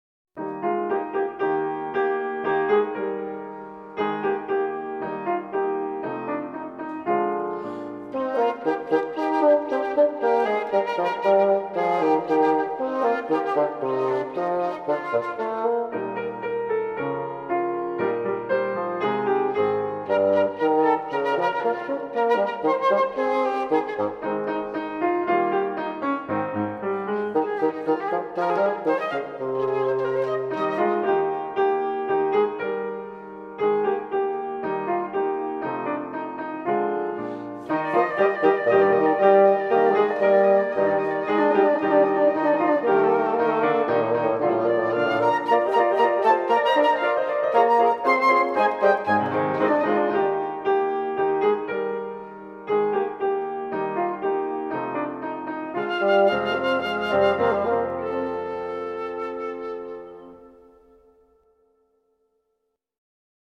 Suite for Flute, Bassoon and Piano: 1st movement
Recorded in Singapore in 1996.
flute.
bassoon.
piano